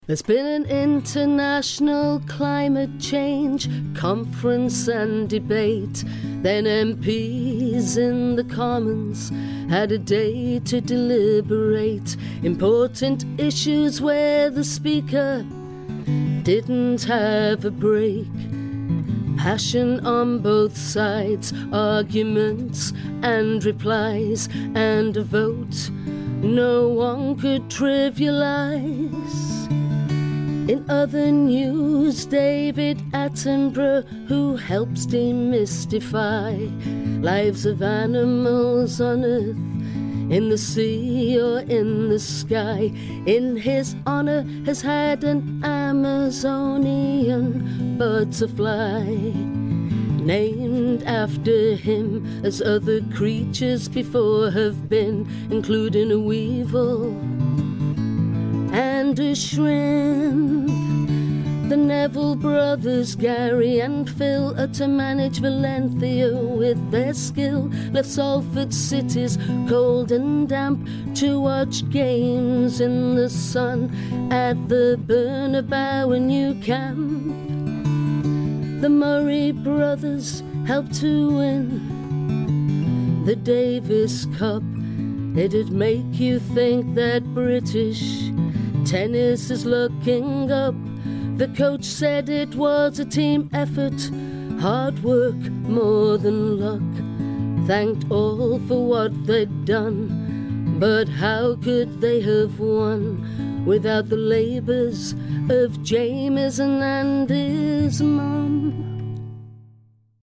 Manchester singer songwriter